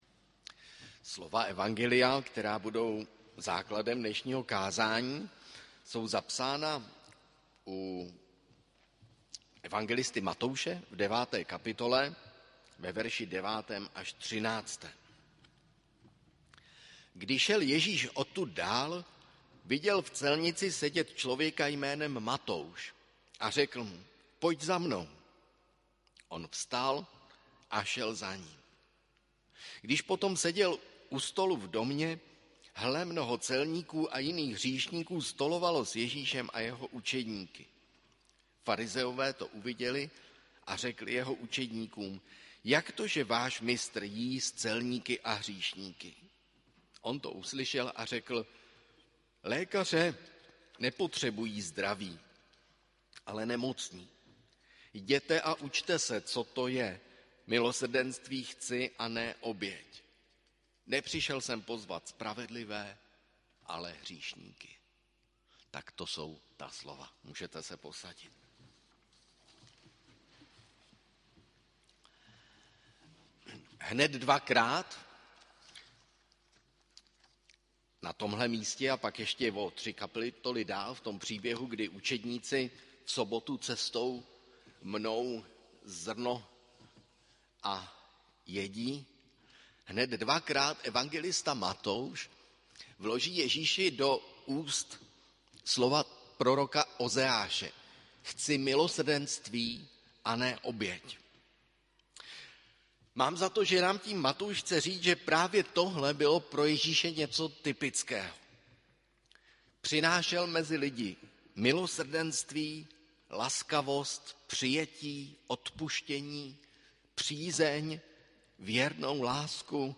První neděle po sv. Trojici 11. června 2023
Kázání